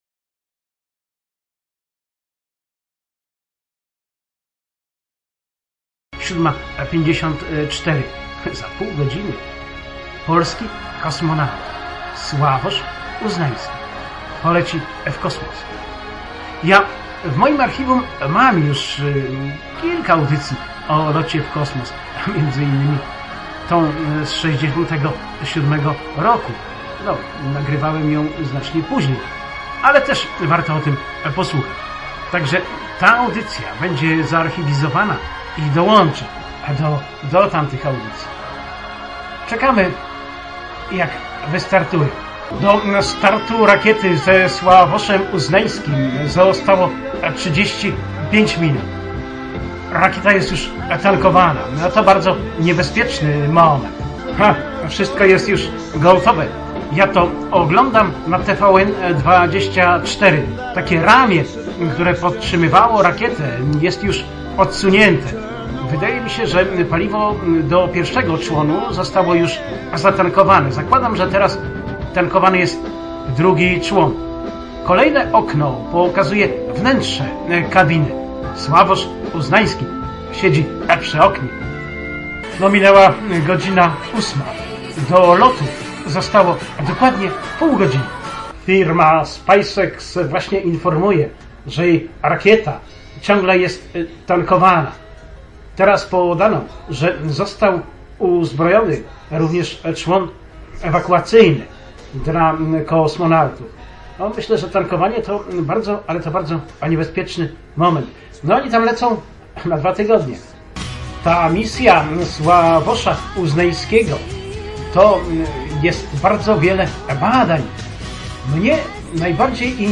Zdjęcie jest aktywne, klikając posłuchamy relacji Radia Maria ze startu i dokowania.